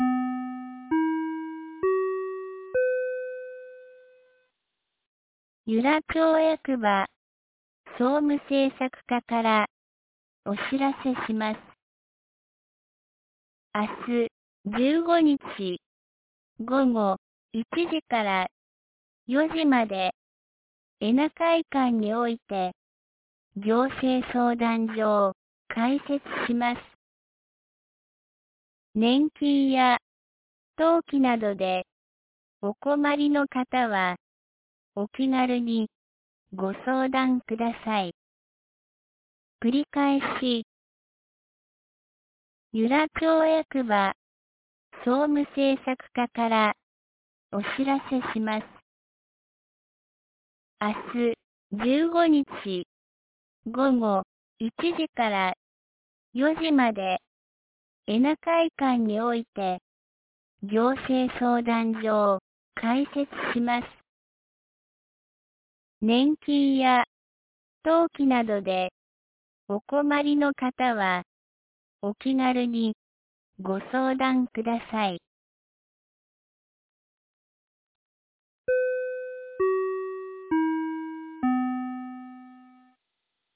2020年10月14日 12時21分に、由良町から全地区へ放送がありました。